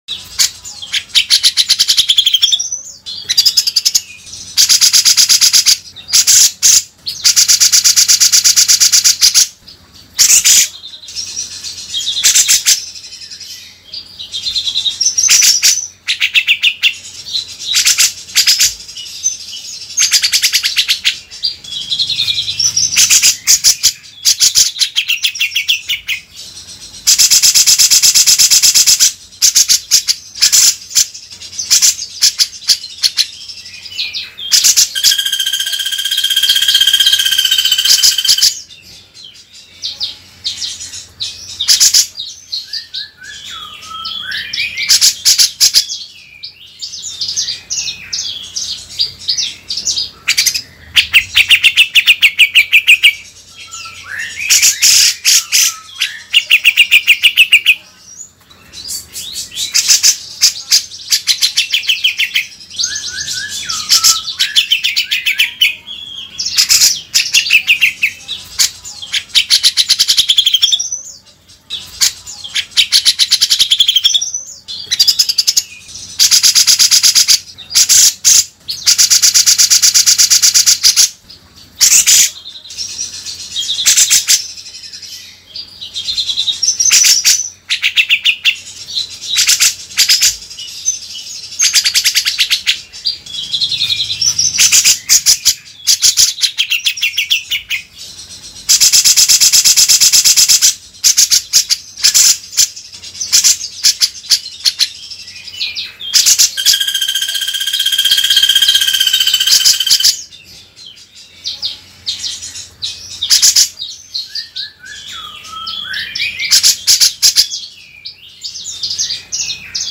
Download Suara Burung Cucak Jenggot Jawa mp3 untuk masteran gacor. Dengan suara Sat Set Sat Set tajem dan mbeset perih, cocok untuk melatih cucak jenggot agar rajin berkicau.
Suara Cucak Jenggot Jawa
Suara kasaran ini sangat efektif untuk mencetak burung berkualitas dan membuat cucak jenggot semakin gacor.
suara-burung-cucak-jenggot-jawa-id-www_tiengdong_com.mp3